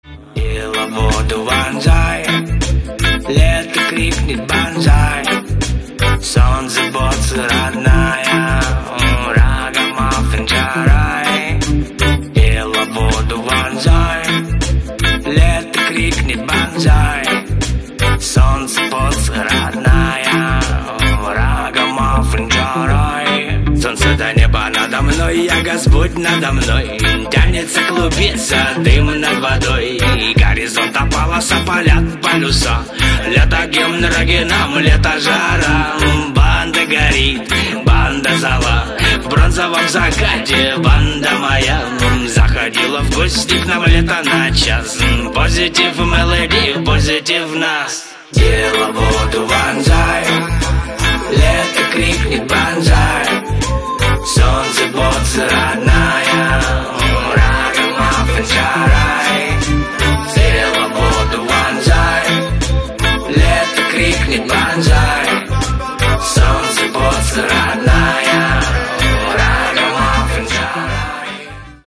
• Качество: 224, Stereo